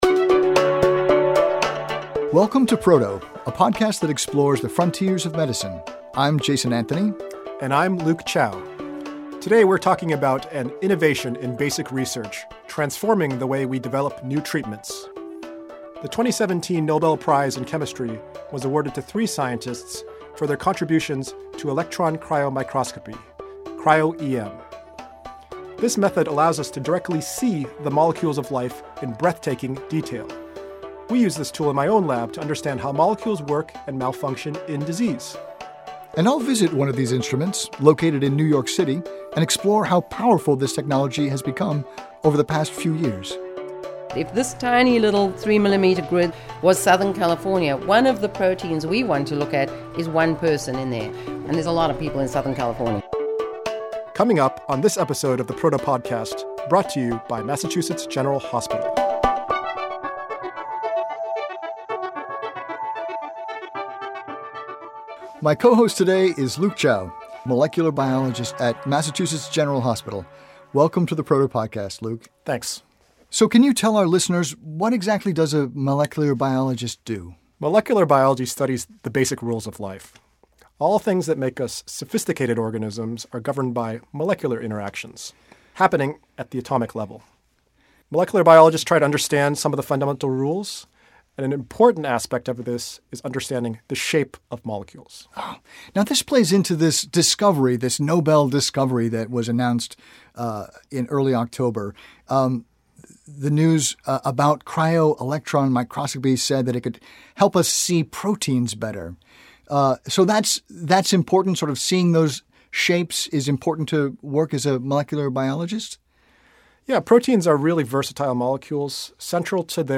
The podcast also travels to the New York Structural Biology Center